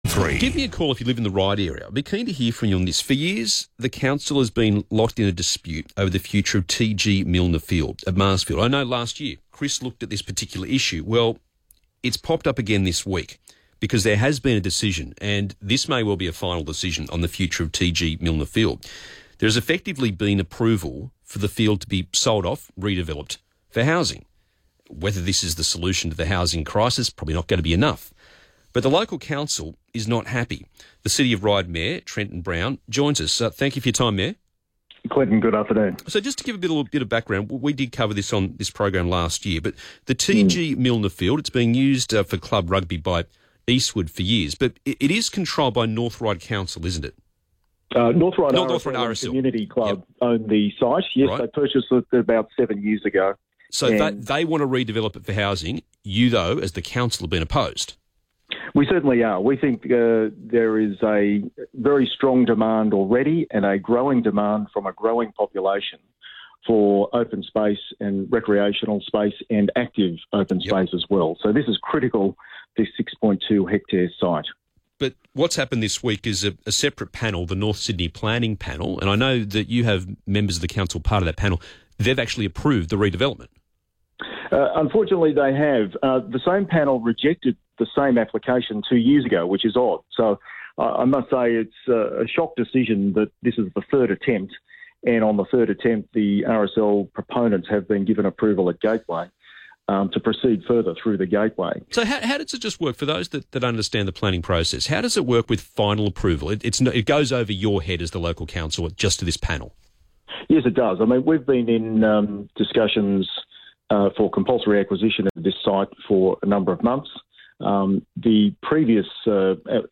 2GBRadio_2025-06-13-interview.mp3